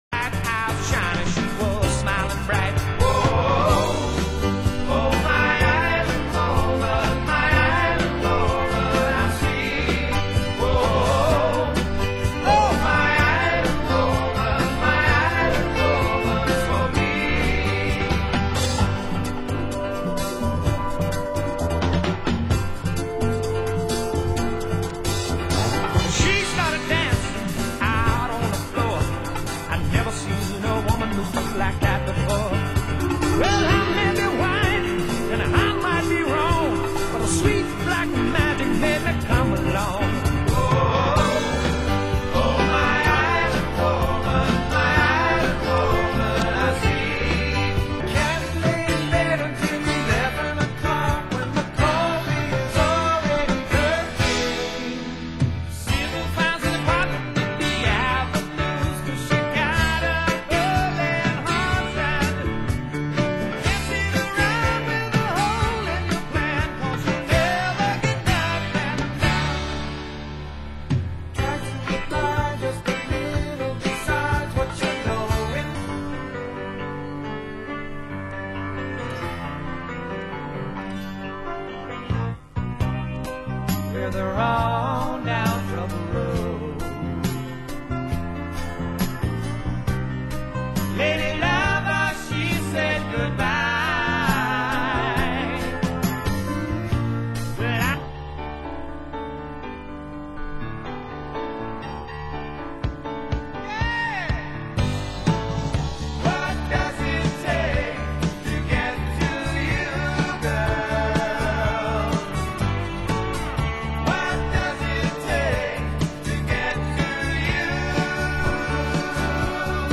Genre: Soul & Funk